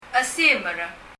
« clock 時計 table テーブル » door ドア chesimer [? əsi(:)mər] 「ドア」です。